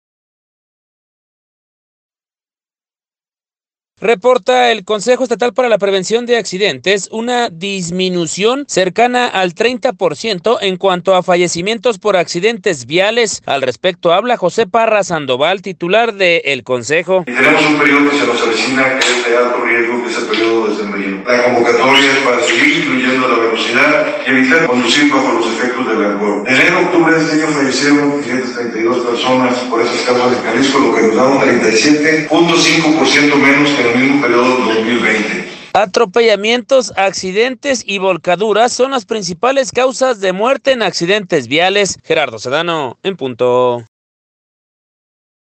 Reporta el Concejo Estatal para la Prevención de Accidentes, una disminución cercana al 30 por ciento en cuanto a fallecimientos por accidentes viales, al respecto habla, José Parra Sandoval, titular del Consejo.